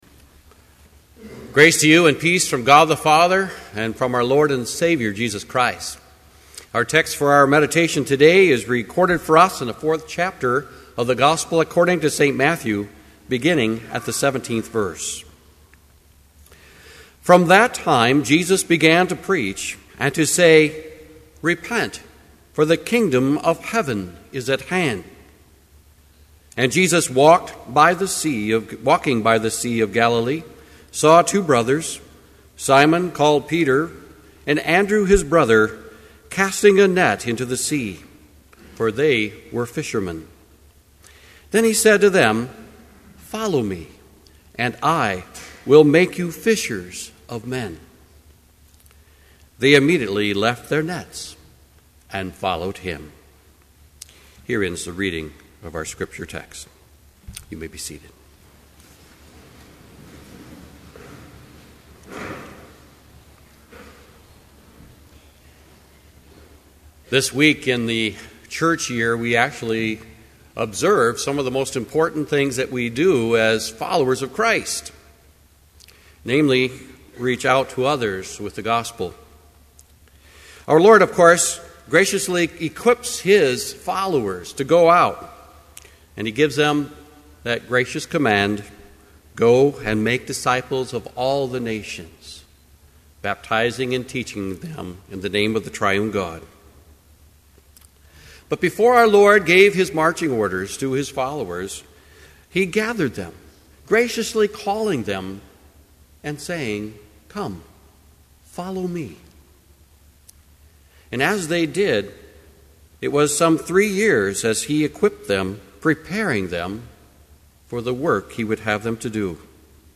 Complete service audio for Chapel - January 26, 2012